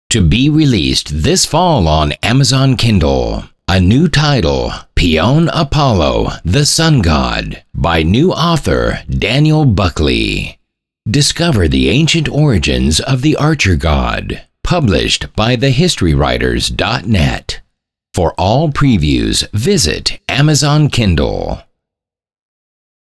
PAIAWON APOLLO THE SUN GOD AUDIO ADVERT FREE E BOOK OFFER friDAY 27th FEB